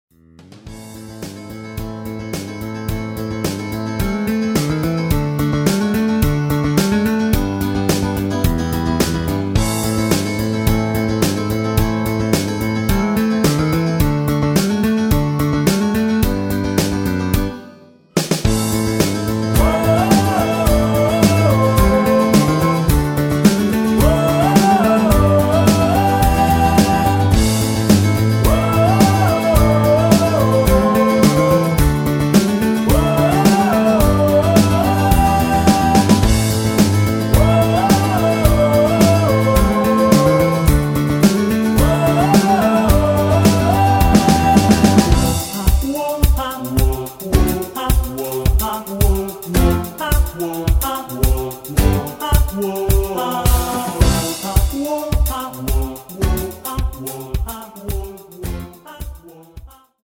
가사의 워어어어어어~ 부분이 추가된 MR 입니다.(미리듣기 참조)
Am
앞부분30초, 뒷부분30초씩 편집해서 올려 드리고 있습니다.